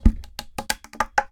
Pen_Beats.ogg